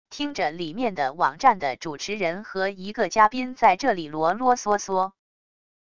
听着里面的网站的主持人和一个嘉宾在这里啰啰嗦嗦wav音频